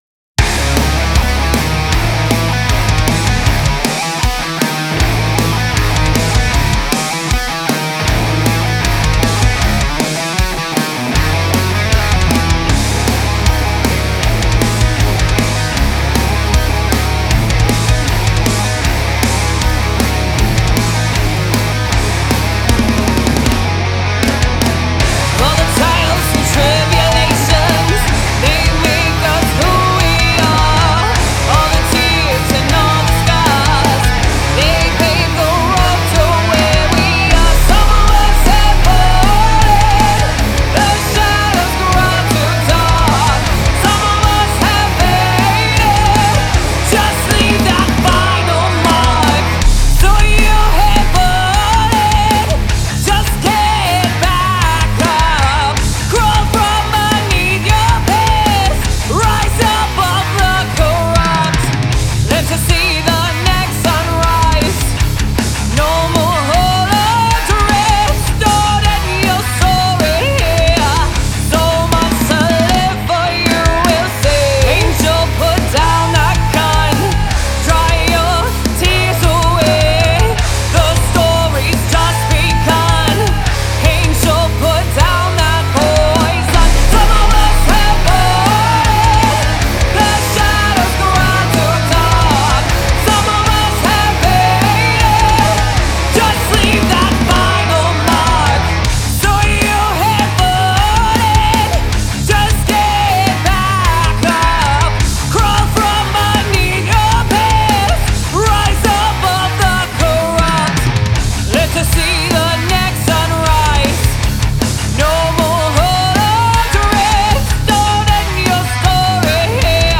female-fronted hard rock and groove metal band